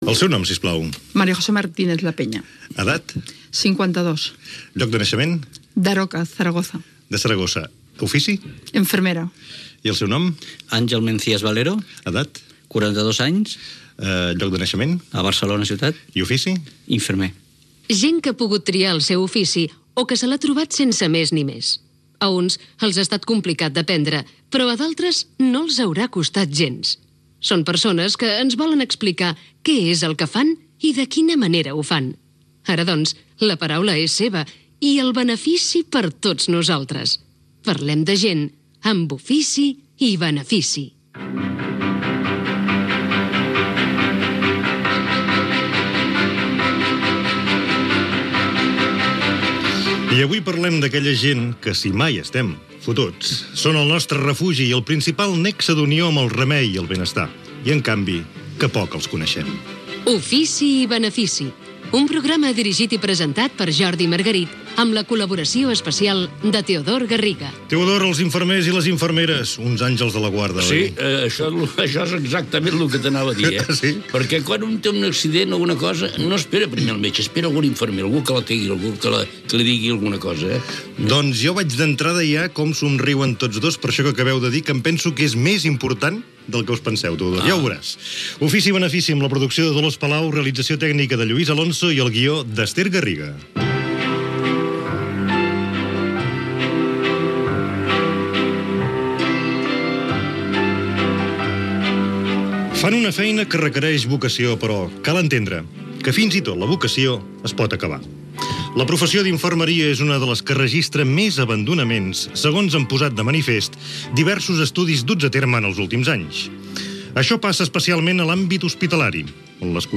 Presentació dels invitats. Careta del programa, equip, espai dedicat a les inferemeres i infermers: comentari inicial, descripció de l'ofici i entrevista a dos professionals
Divulgació